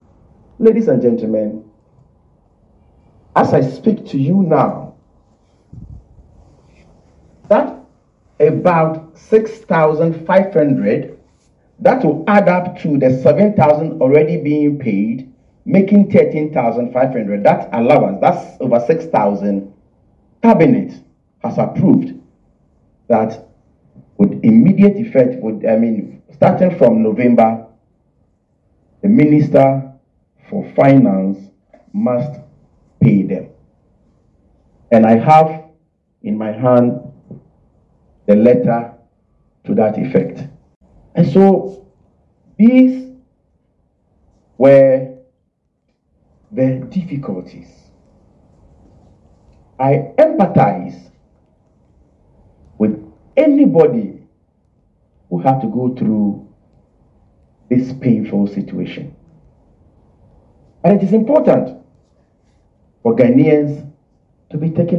Speaking at a press conference amid growing agitation from nurses’ unions across the country, the Minister revealed that the Ministry of Finance has received Cabinet approval to release funds for the payments.